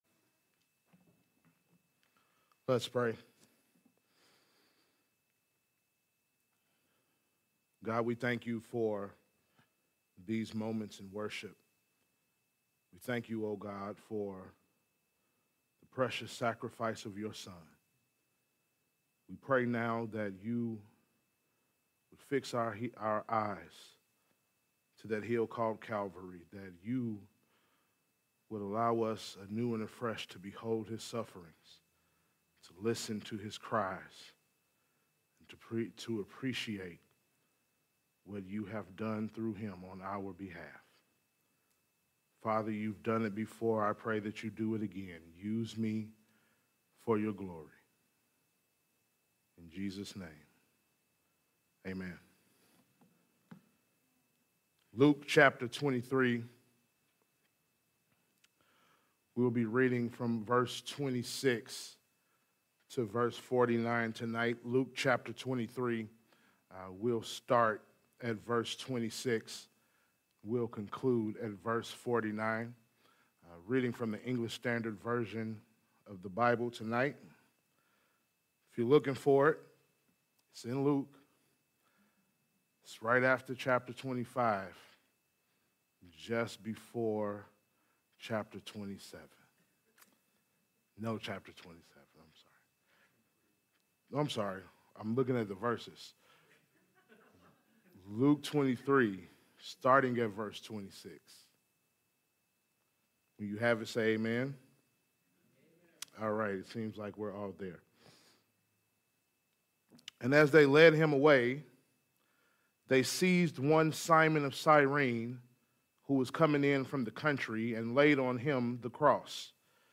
Service Type: Good Friday Service
Good Friday Sermons